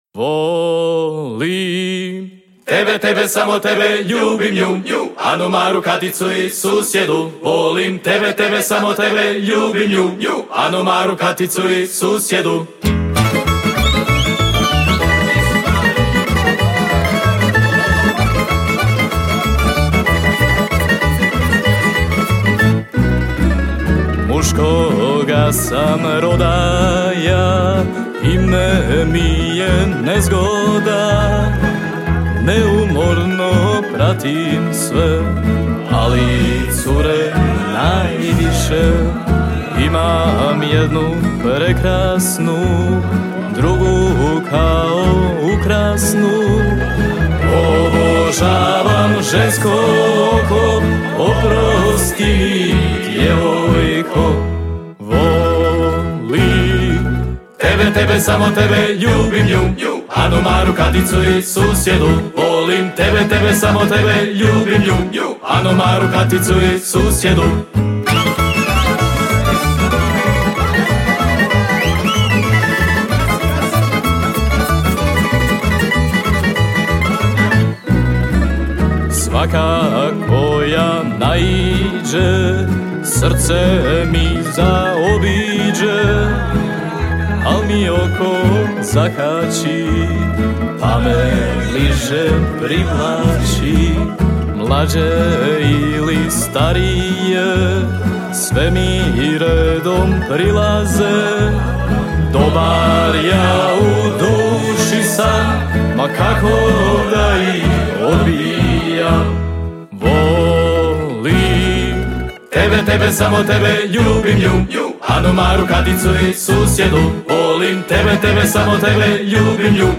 Festival pjevača amatera
Zvuci tamburice odzvanjali su prepunom dvoranom vatrogasnog doma u Kaptolu do kasnih noćnih sati.